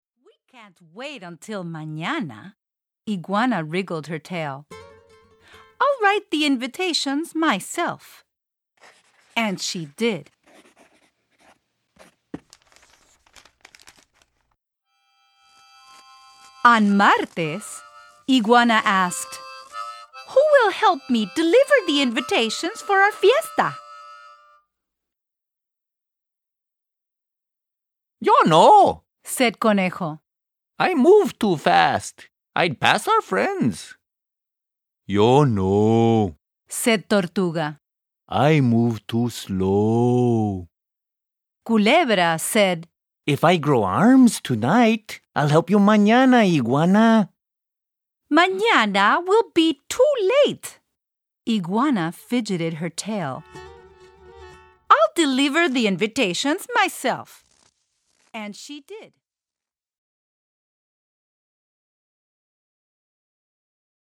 Spanish Readalongs